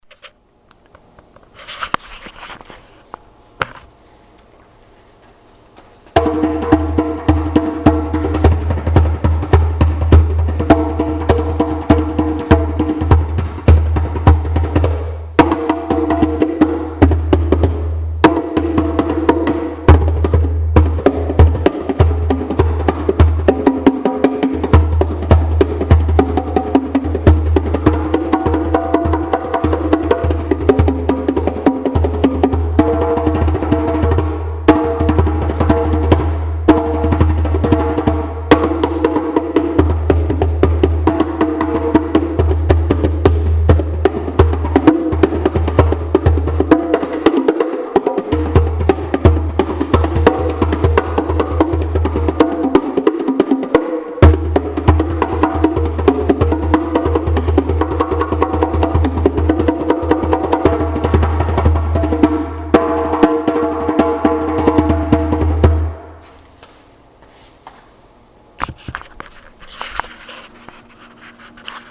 One Minute Three Drums
one-minute-three-drums1.mp3